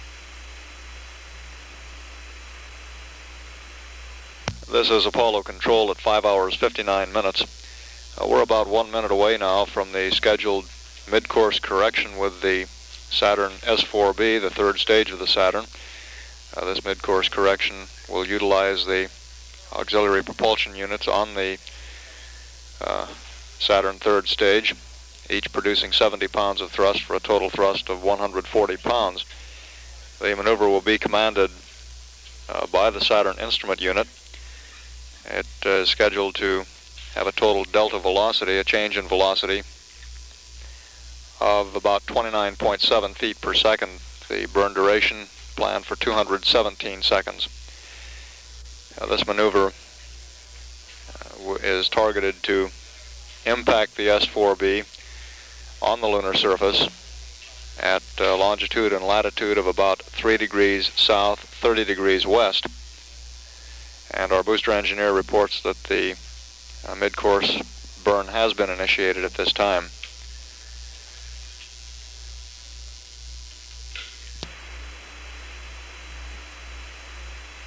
PAO loop.